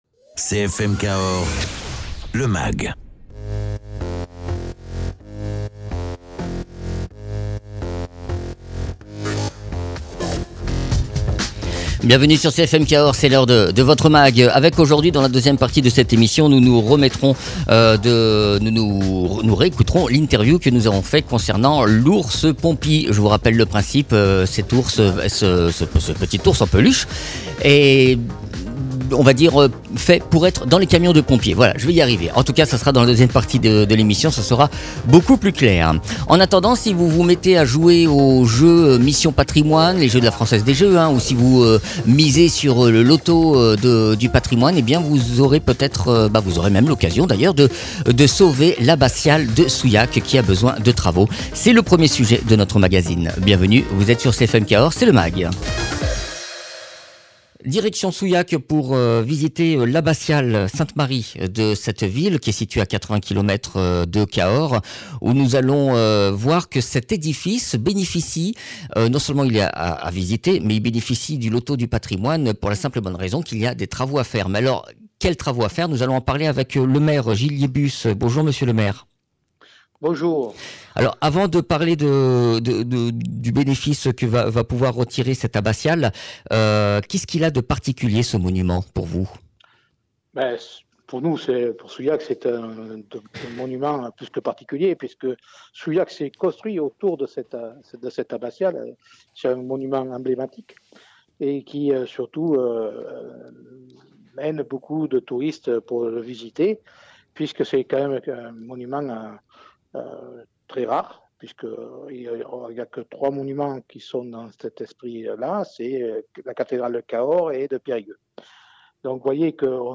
Invité(s) : Gilles Liébus, Maire de Souillac.